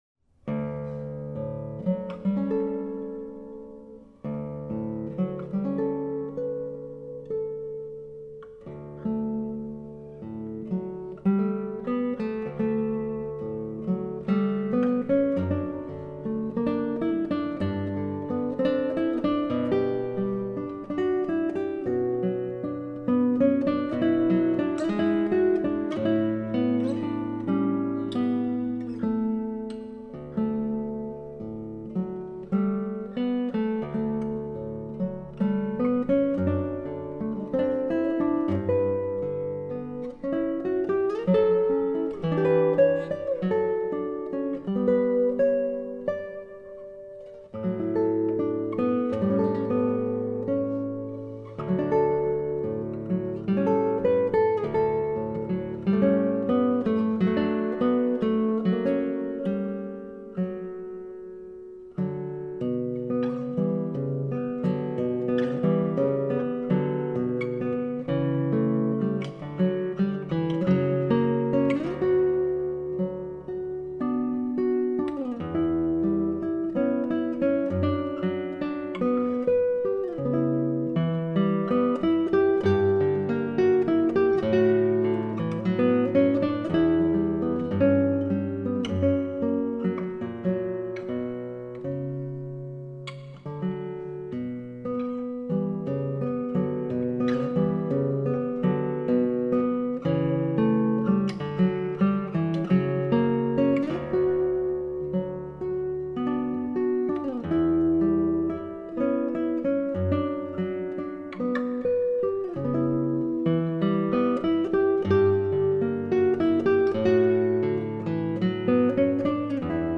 Classical (View more Classical Guitar Music)
classical guitar